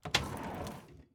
open-wood.ogg